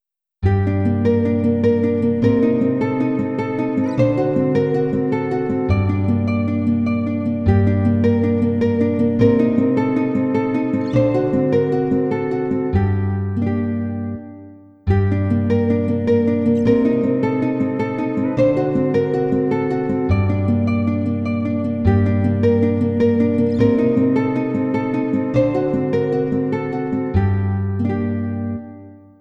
着信メロディ
尚、着メロの雰囲気を醸すために原曲のピッチを2度近く上げており、長さは30秒程度としています。